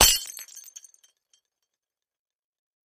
Kitchenware
Kitchen Glass Type 3 Crash